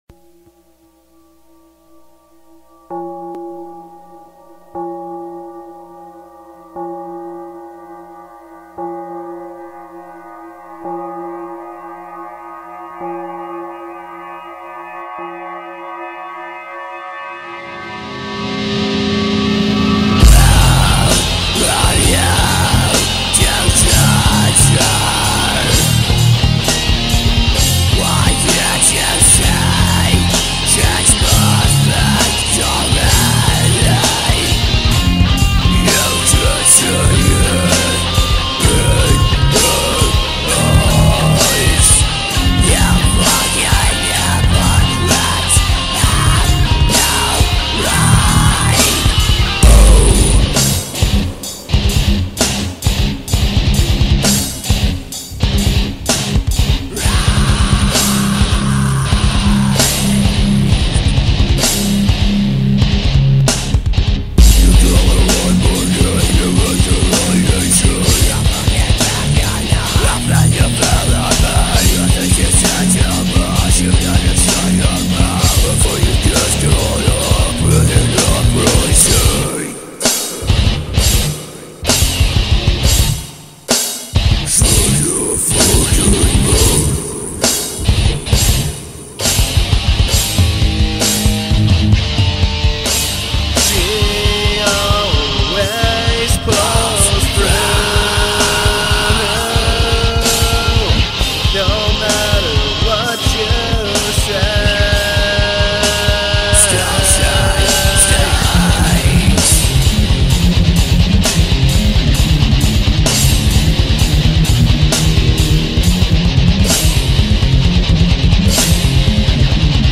Original MLP Fan Music